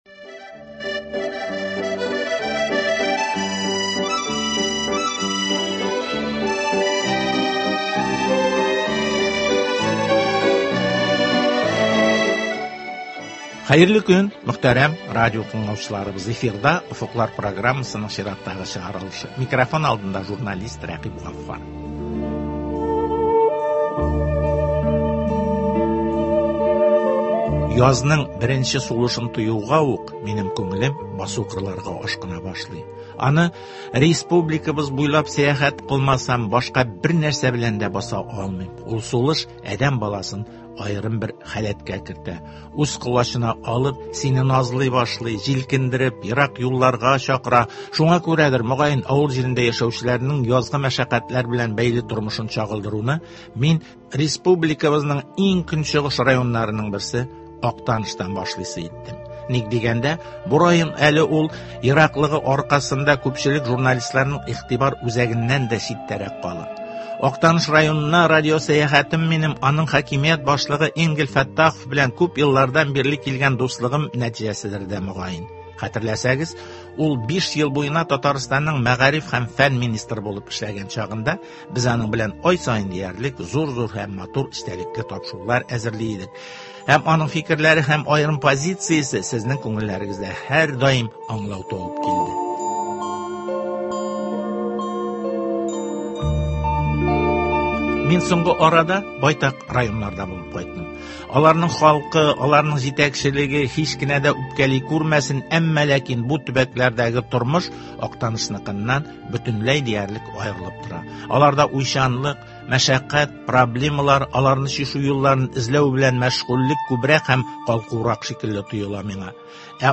Актаныш районында яшь буынны тәрбияләү, райондагы предприятиеләр өчен кадрлар әзерләү һәм авыл хуҗалыгы тармагының бүгенге торышы, аның үсешен тәэмин итү чаралары хакында хакимият башлыгы Энгель Нәвап улы Фәттахов белән әңгәмә.